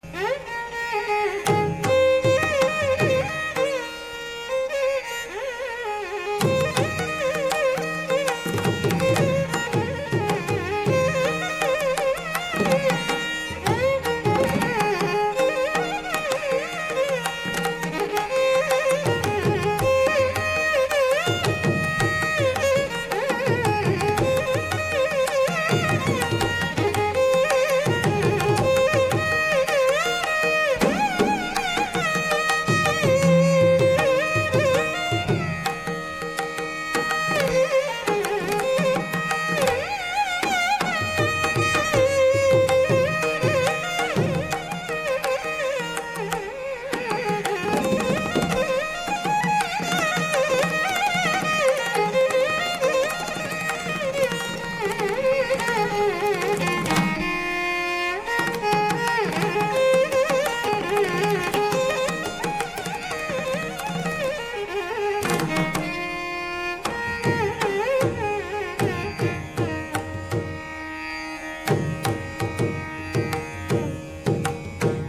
violin
mrdangam
Anupallavi begins in the uttara anga. (uttarānga)
Pallavi is restated at conclusion of anupallavi